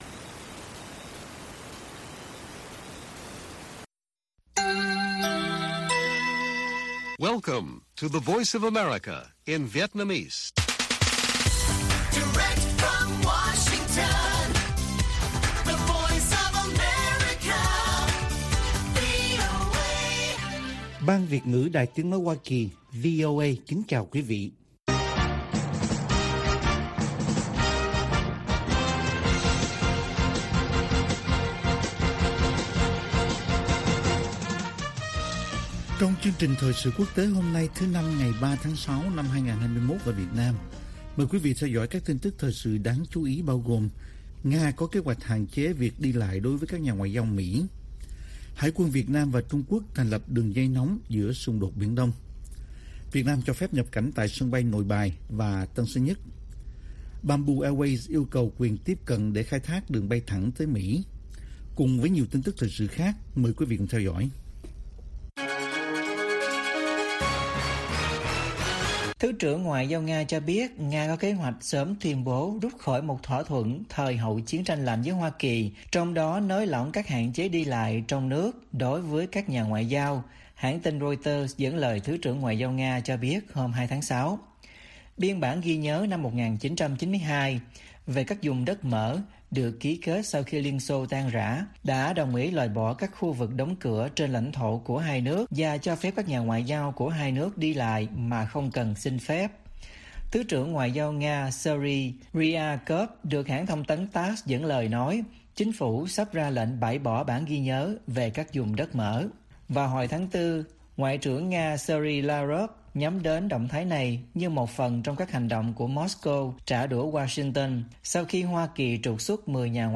Bản tin VOA ngày 3/6/2021